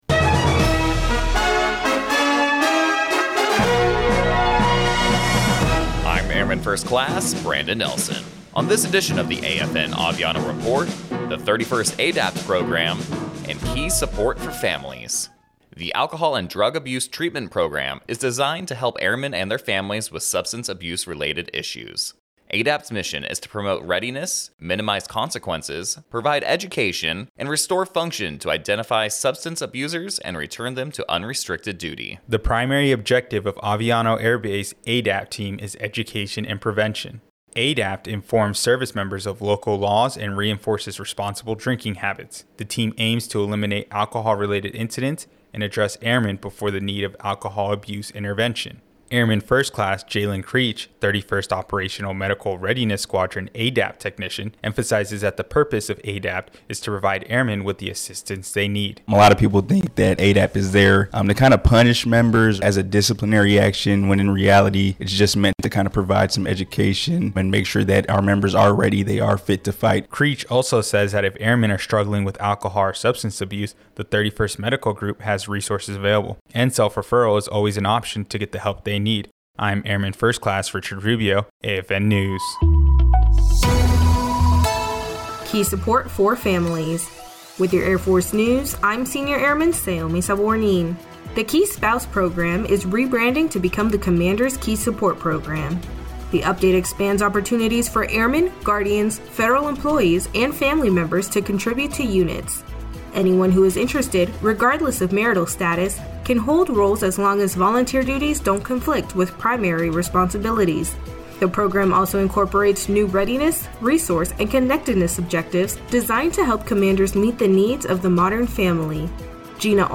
American Forces Network (AFN) Aviano radio news reports on the 31st Medical group’s Alcohol and Drug Abuse Prevention and Treatment Program and how it provides education, training, and resources to the airmen of Aviano Air Base.